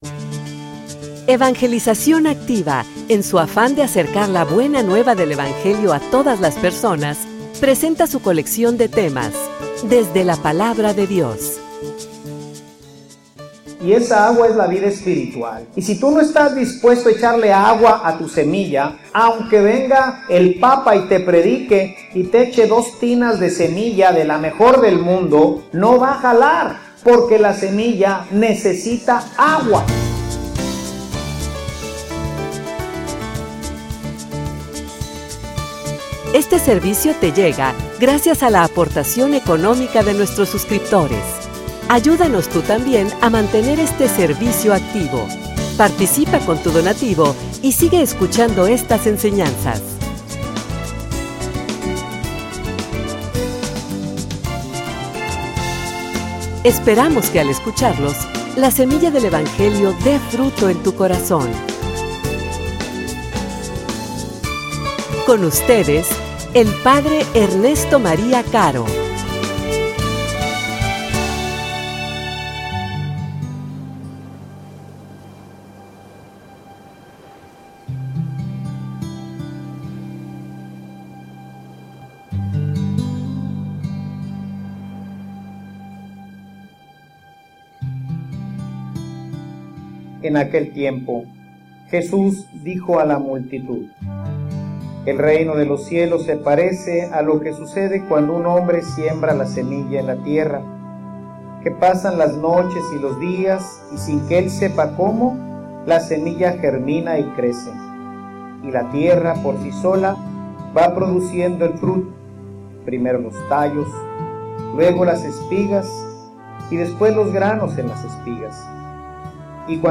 homilia_Un_Dios_de_procesos.mp3